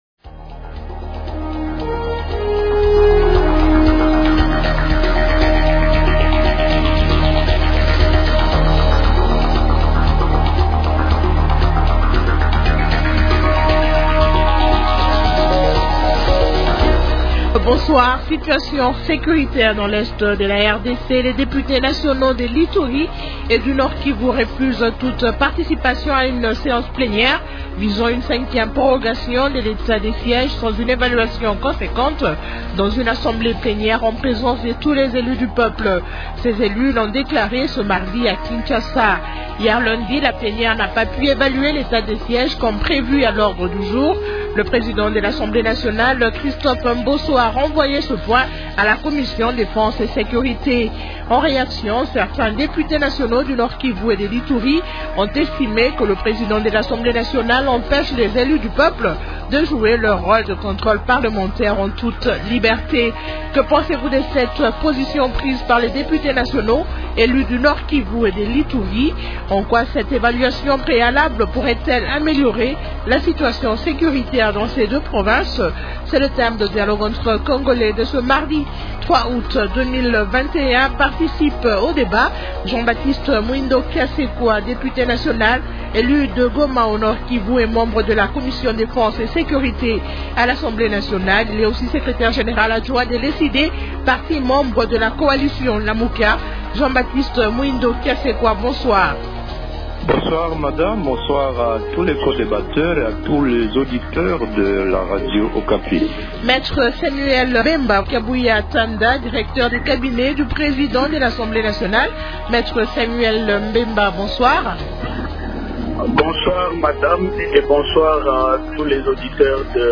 -Jean-Baptiste Muhindo Kasekwa, député national élu de Goma au Nord-Kivu et membre de la commission défense et sécurité à l’Assemblée nationale.